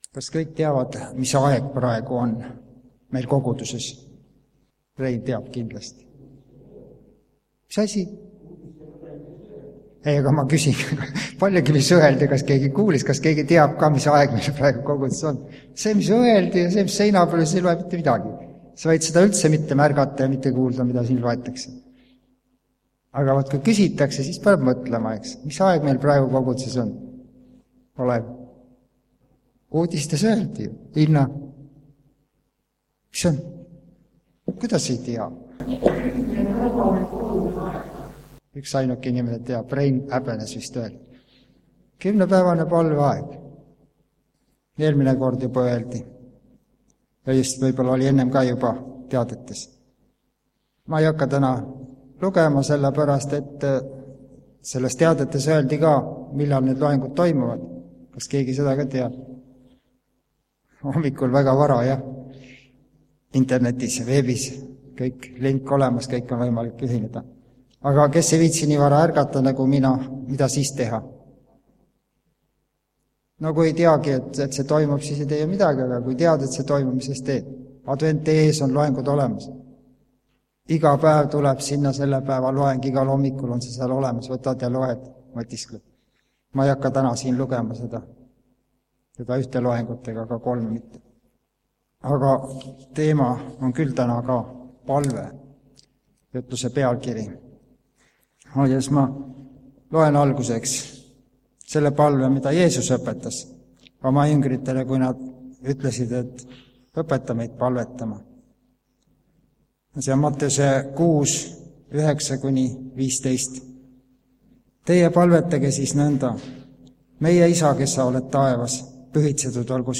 PALVE (Haapsalus)
Jutlused